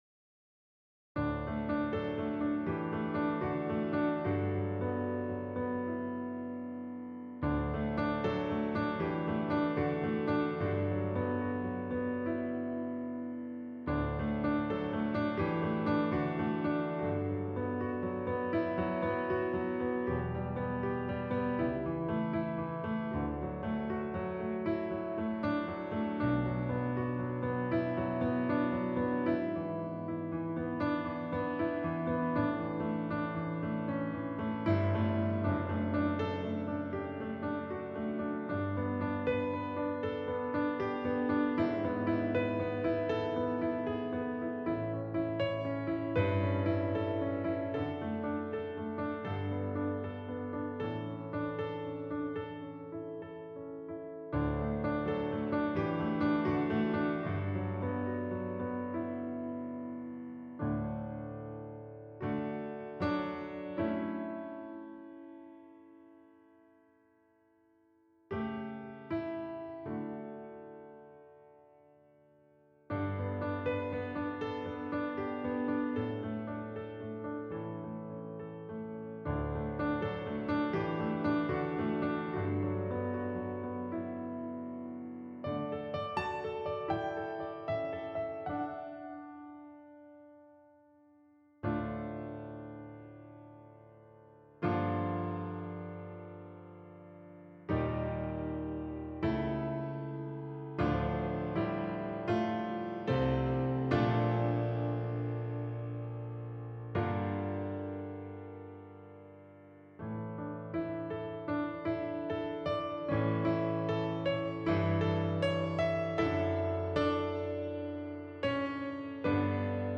Instrumentation: 2 Violins, Piano
An arrangement for 2 violins with piano accompaniment
You will find just detaché legato bowing throughout.
The final triumphant stanza is in A Major.
Download accompaniment recording